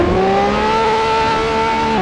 Index of /server/sound/vehicles/lwcars/lam_reventon